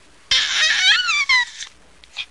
Baby Goo Sound Effect
Download a high-quality baby goo sound effect.
baby-goo-2.mp3